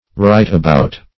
right-about - definition of right-about - synonyms, pronunciation, spelling from Free Dictionary
Right-about \Right"-a*bout`\, n. [Right, adv. + about, adv.]